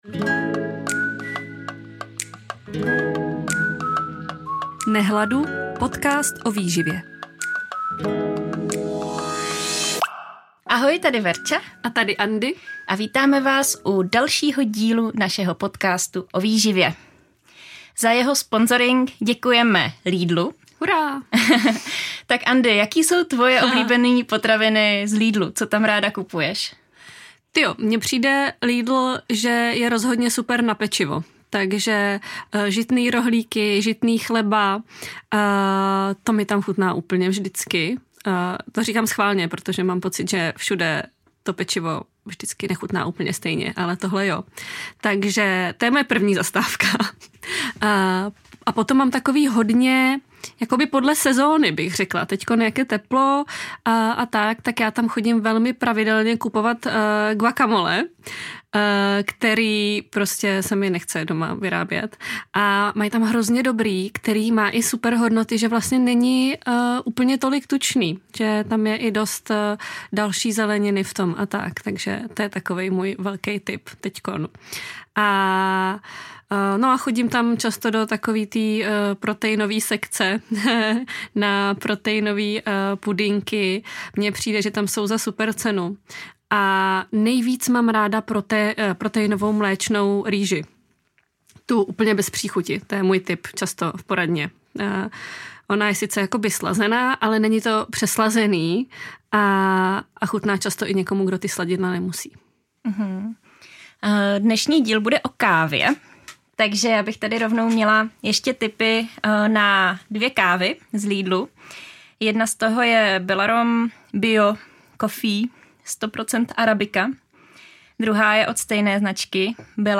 Na procházce jsme pro vás naučily extra díl věnovaný novinkám ze světa výživy.